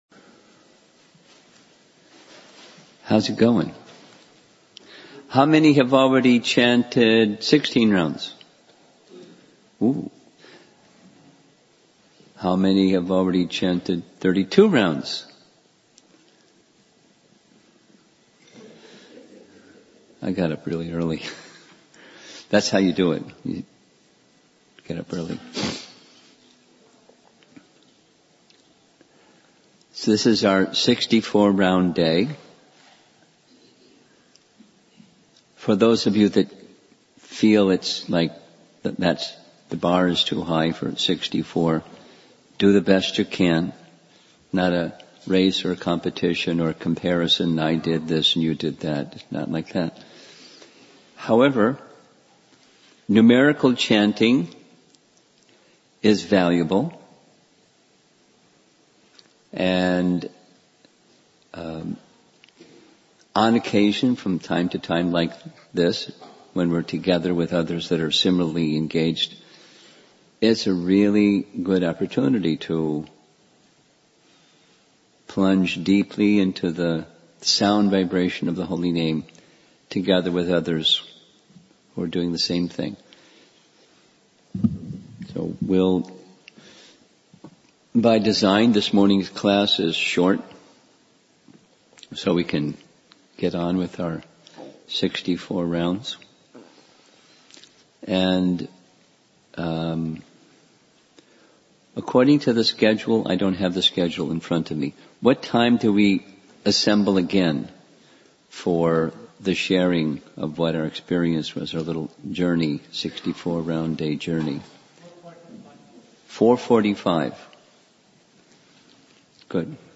Previous Lecture 2019 Central New Jersey Japa Retreat